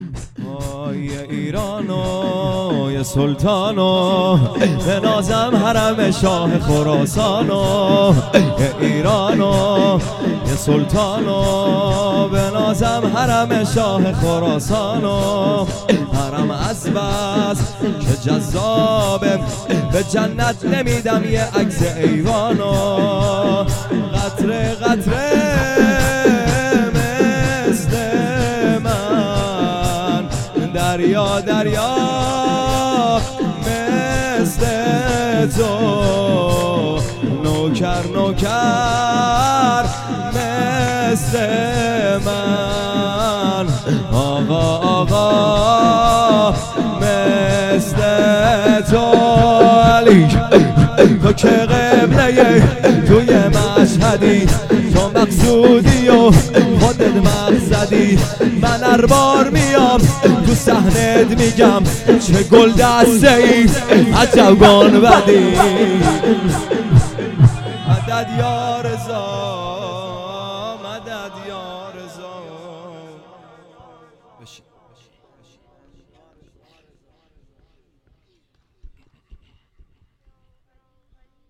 شور _ یه ایران و یه سلطان